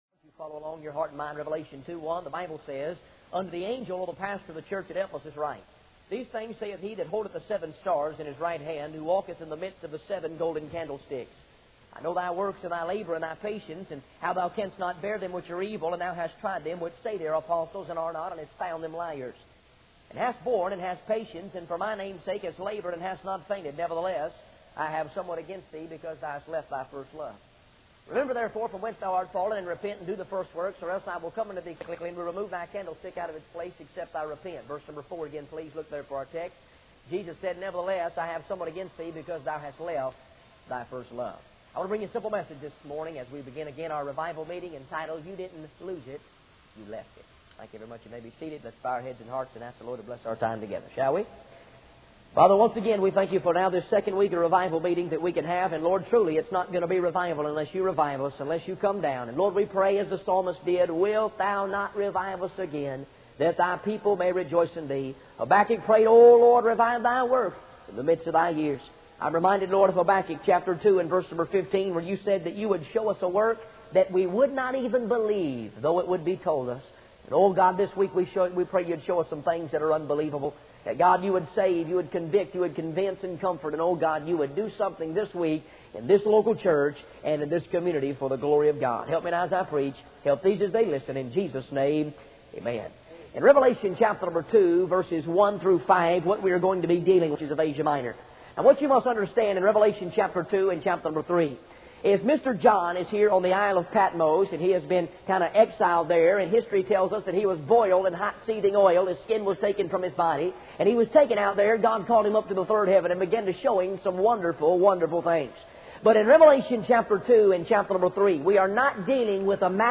In this sermon, the preacher discusses the message from God to the church.